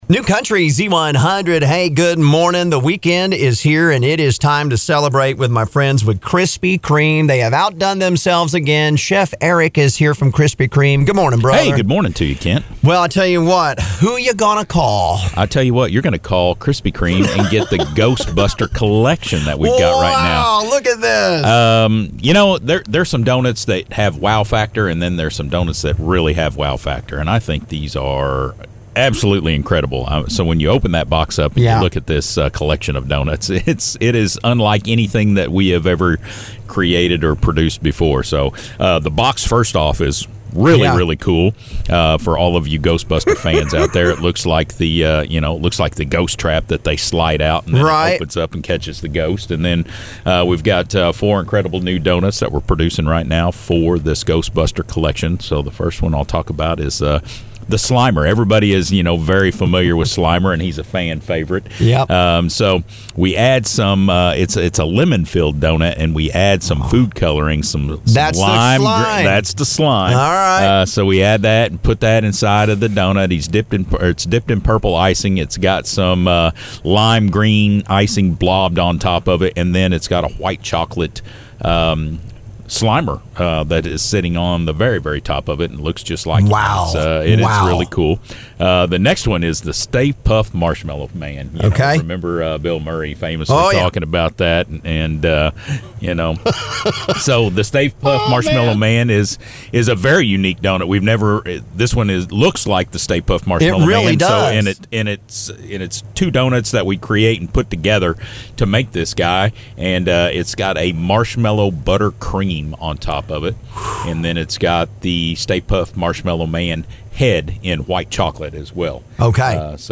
(scroll to bottom for interview)  Go get 'em before you get slimed!!